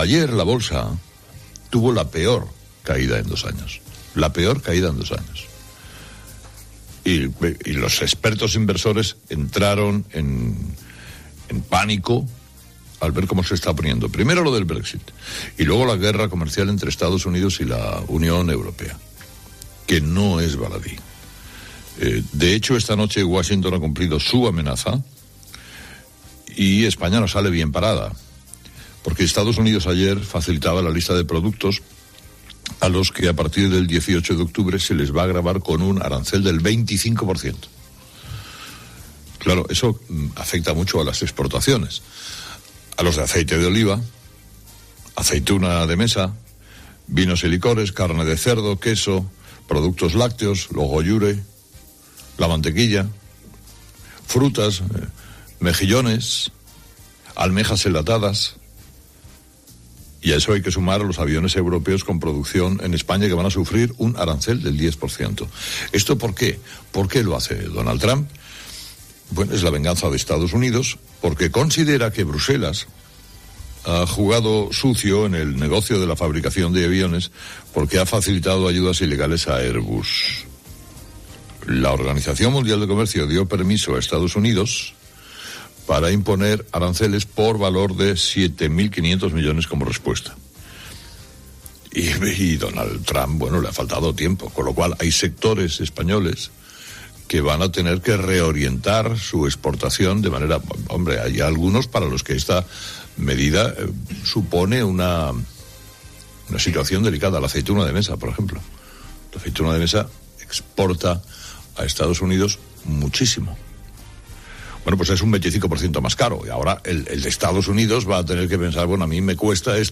Carlos Herrera dedicó parte de su monólogo de las 06.00 a analizar la situación económica de España tras los nuevos aranceles de Trump a la UE, y la propuesta de Brexit esbozada por Johnson.